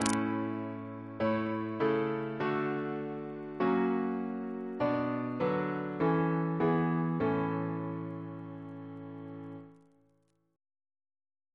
CCP: Chant sampler
Single chant in A Composer: Sir George Elvey (1816-1893), Organist of St. George's Windsor; Stephen's brother Reference psalters: ACB: 383; CWP: 210; H1940: 644; H1982: S37; PP/SNCB: 164; RSCM: 175